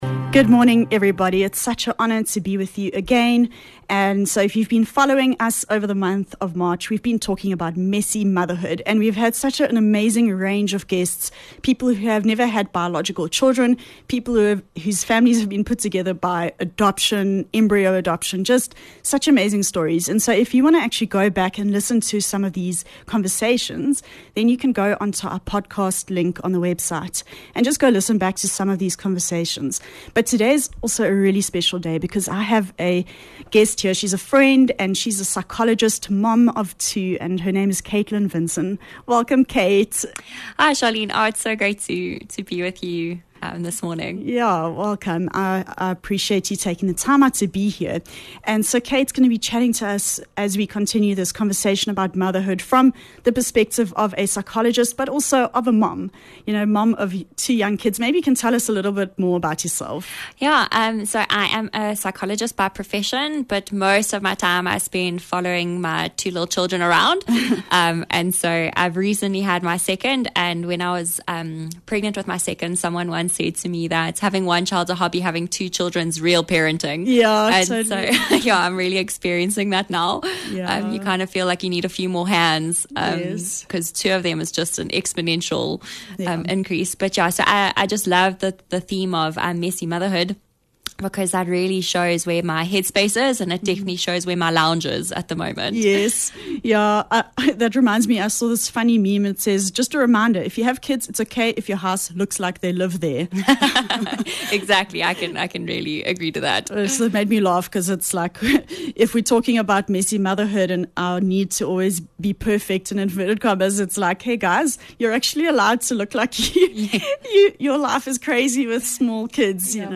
20 Mar Messy Motherhood - Interview